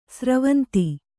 ♪ sravanti